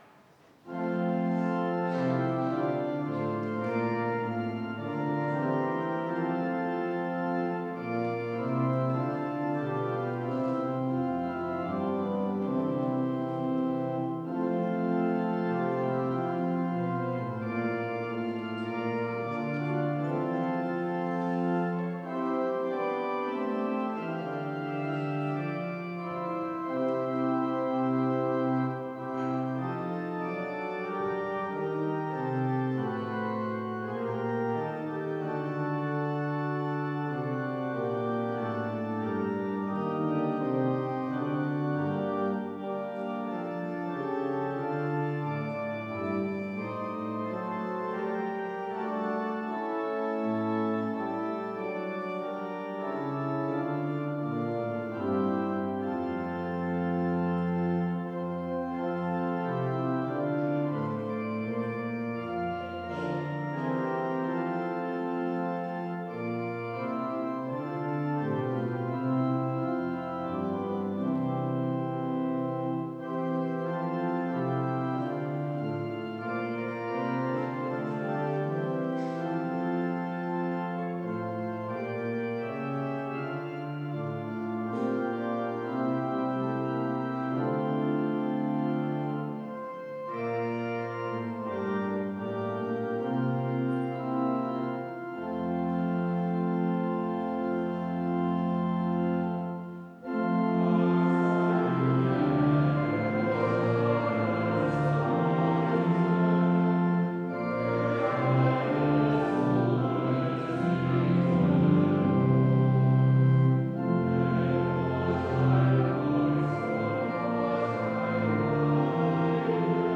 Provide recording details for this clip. Audiomitschnitt unseres Gottesdienstes am Palmsonntag 2025.